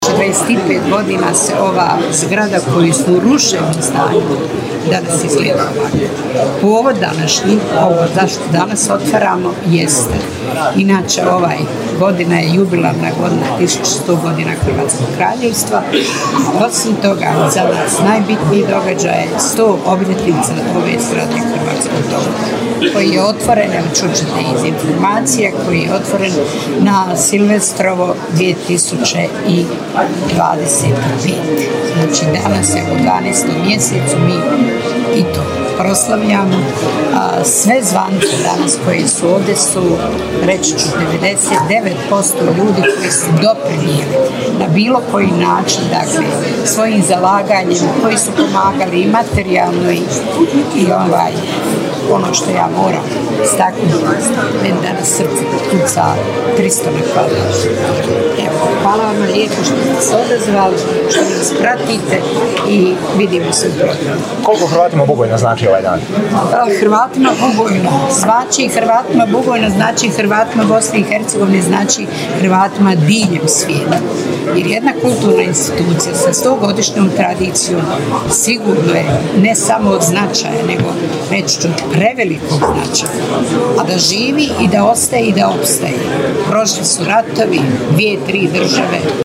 Otvorena je obnovljena kino-kazališna dvorana u Hrvatskom domu.